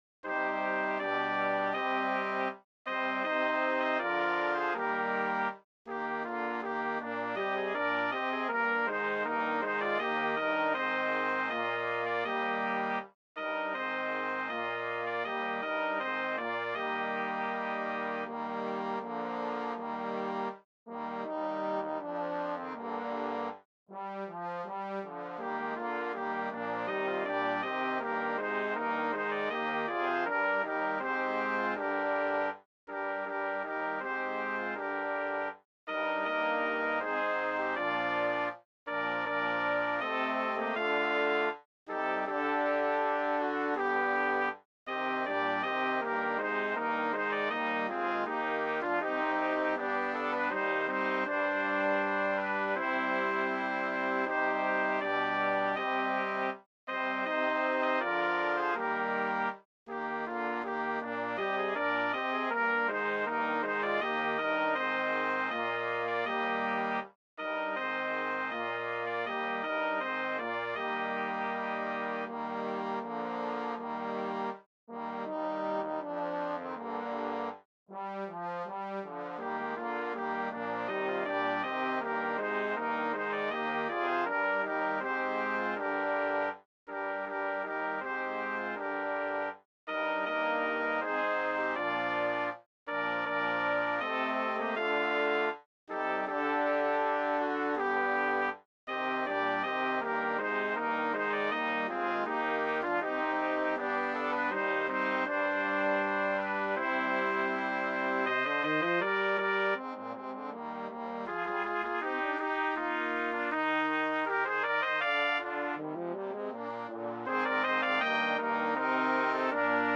BRASS QUINTET
STANDARD BRASS QUINTET